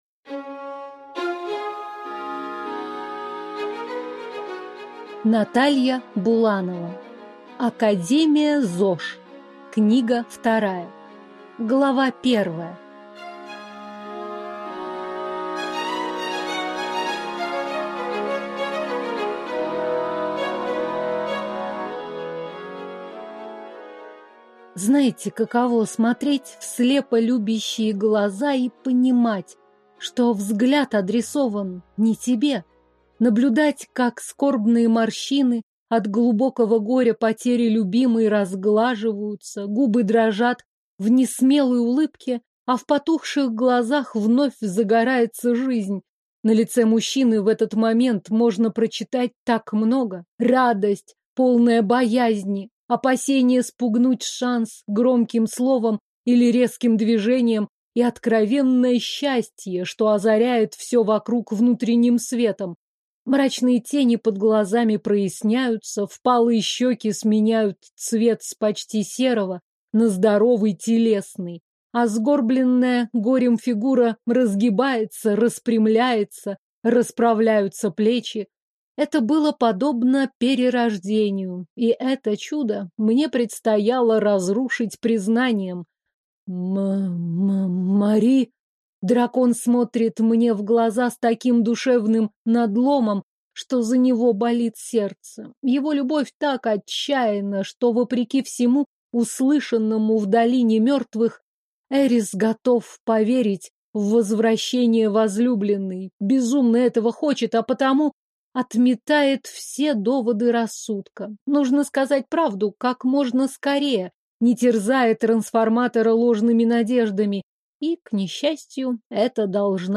Аудиокнига Академия ЗОЖ. Книга 2 | Библиотека аудиокниг